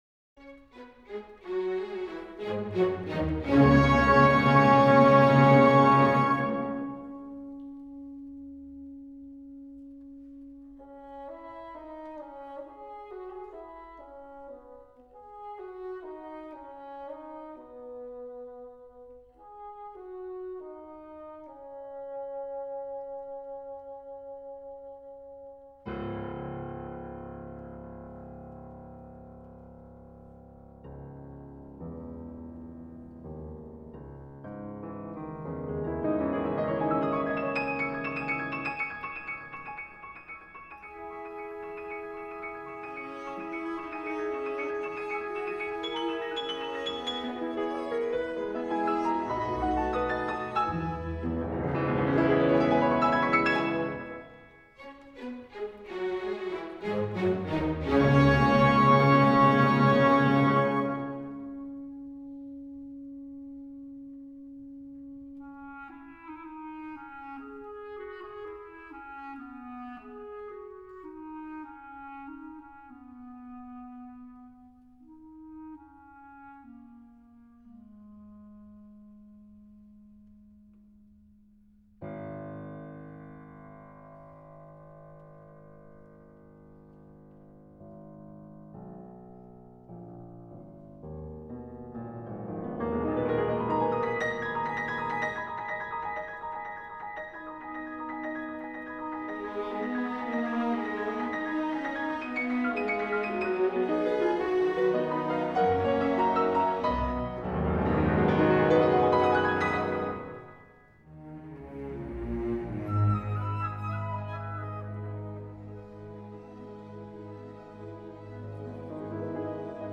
موسیقی کلاسیک: کنسرتو پیانو از ریمسکی کورساکف - Rimsky-Korsakov: Piano Concerto in C-sharp Minor, Op. 30